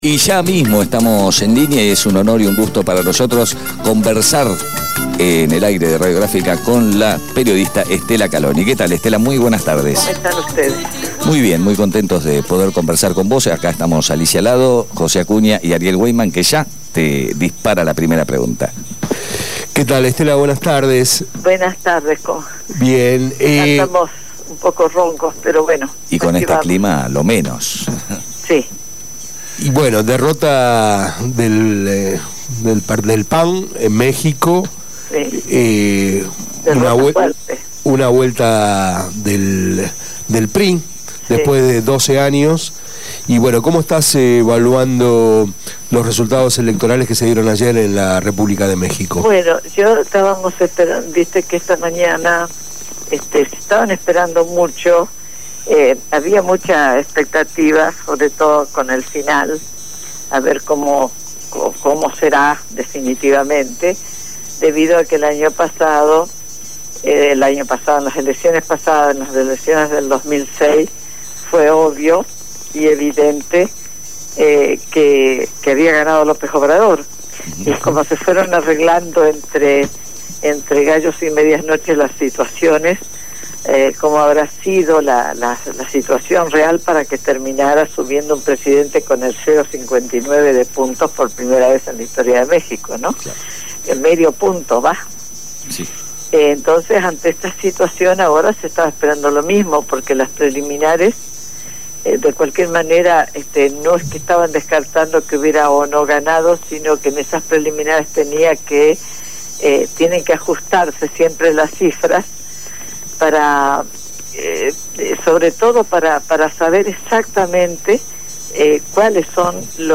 periodista internacional, hablo con Abramos la boca